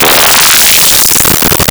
scifi34.wav